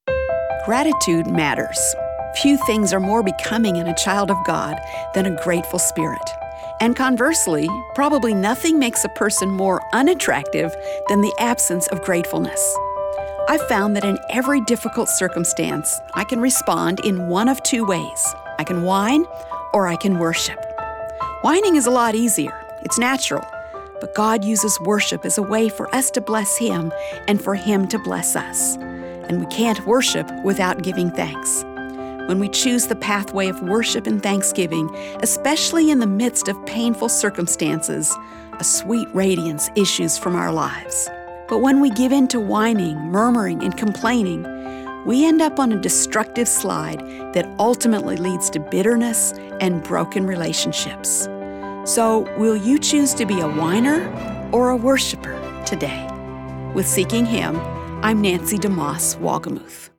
a nationally syndicated, one-minute radio program